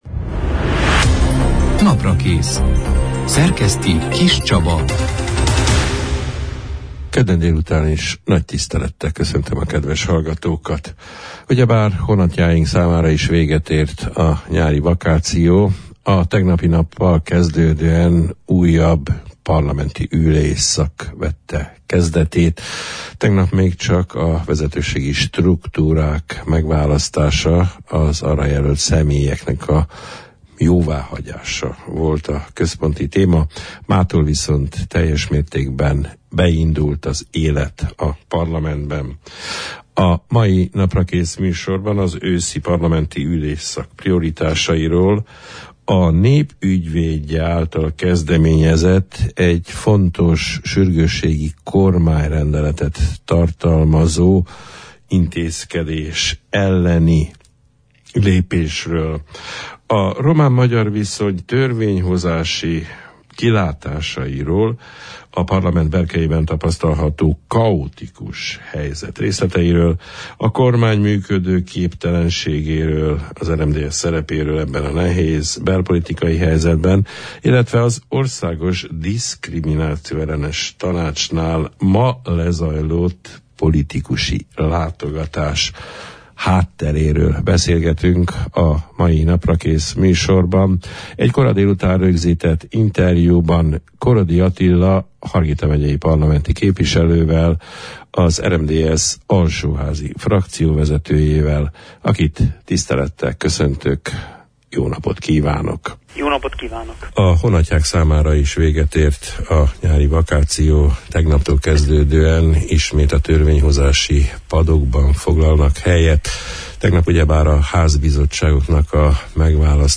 Az őszi parlamenti ülésszak prioritásairól, a Nép Ügyvédje kezdeményezésének várható következényeiről, egy fontos jogszabály vitafolyamatában, a román – magyar viszony törvényhozási kilátásairól, a parlamentben tapasztalható kaotikus állapotokról, a kormány működőképtelenségéről, az RMDSZ szerepéről, ebben a nehéz helyzetben, az Országos Diszkrimináció -ellenes Tanácsnál tett mai látogatásuk okairól beszélgettünk a szeptember 3 – án, kedden elhangzott Naprakész műsorban, egy kora délután rögzített interjúban, Korodi Attila parlamenti képviselővel, alsóházi frakcióvezetővel.